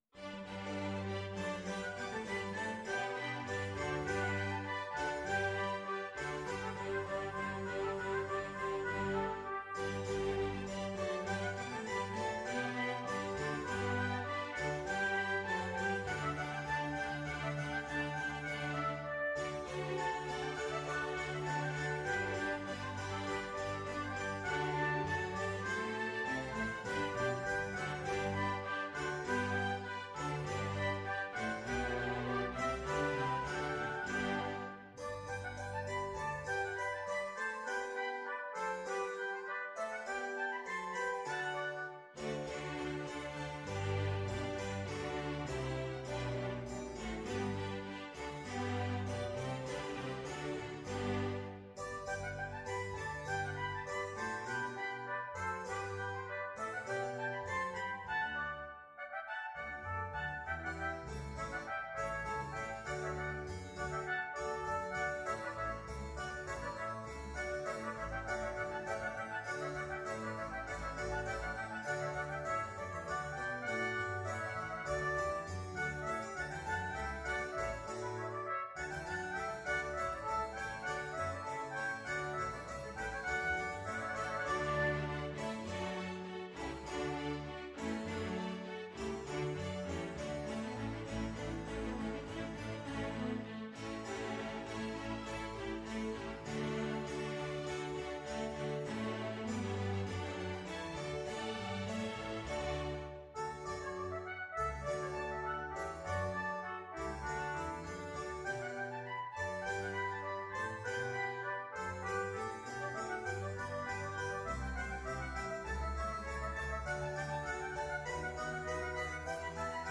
Free Sheet music for Multiple Soloists and Ensemble
Trumpet 1 in CTrumpet 2 in CViolin 1Violin 2ViolaCelloDouble BassHarpsichord
Classical (View more Classical Multiple Soloists and Ensemble Music)